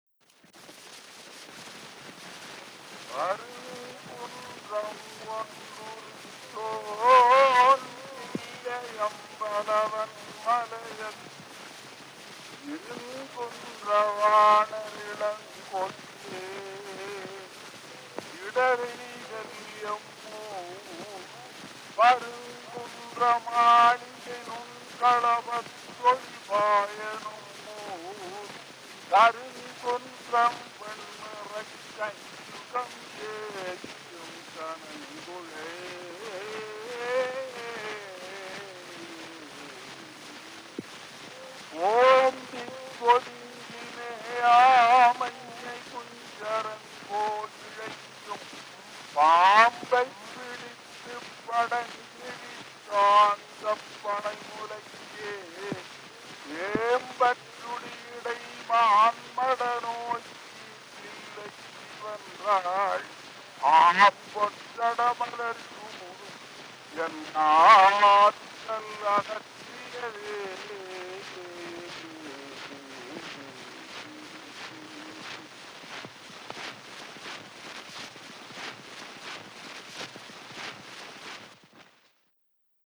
Historical sound recordings